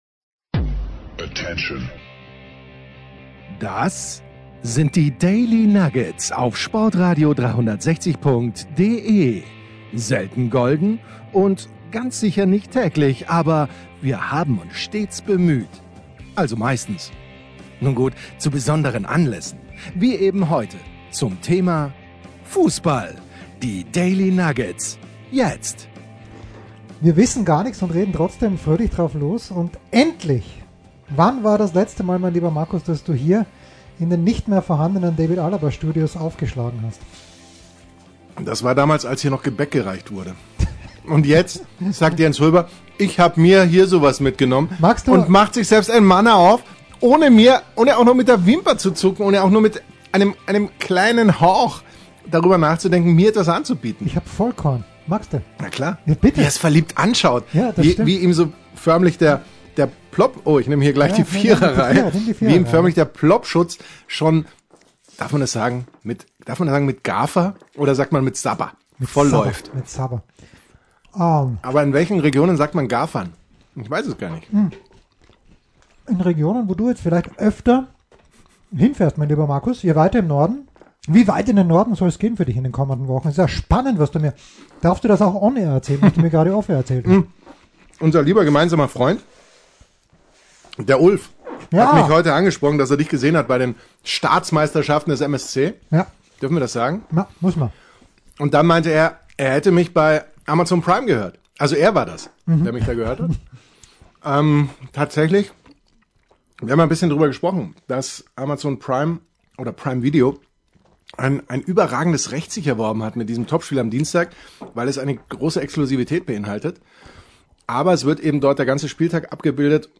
Endlich mal wieder ein Studiobesuch